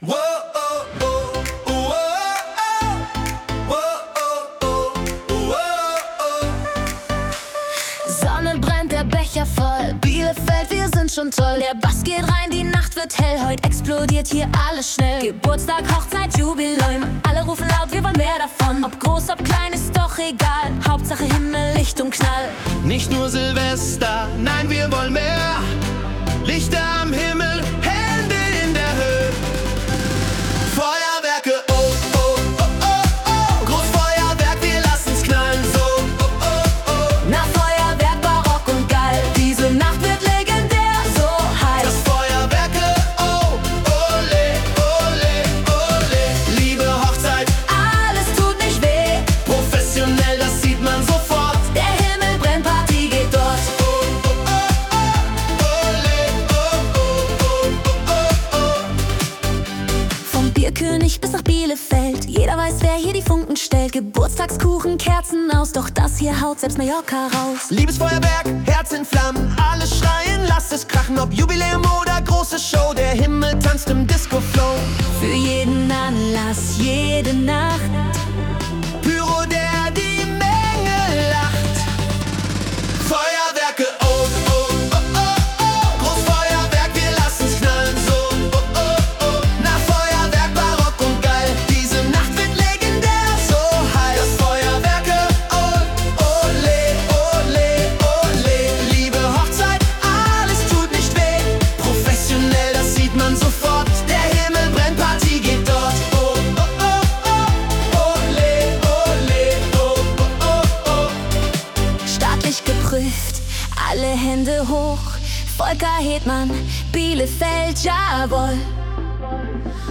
Nun ist auch unser eigener Partysong  endlich da und online!